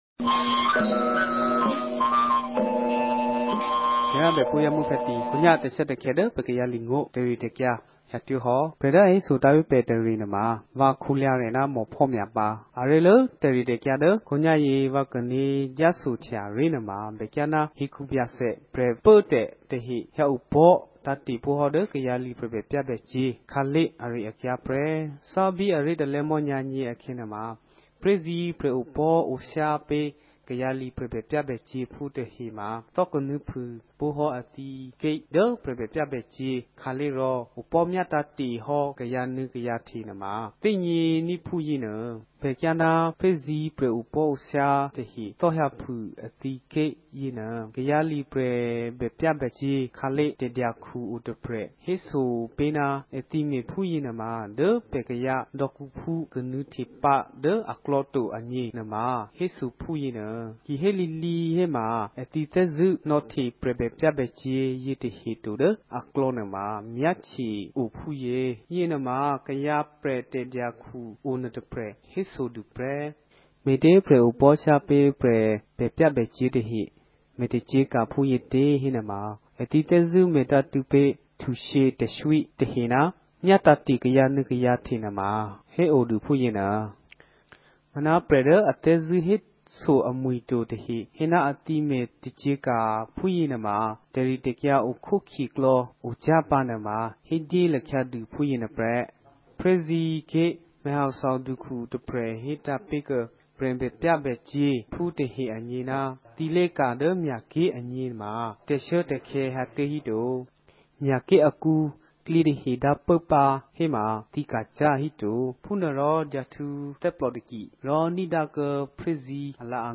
ကရင်နီဘာသာ အသံလြင့်အစီအစဉ်မဵား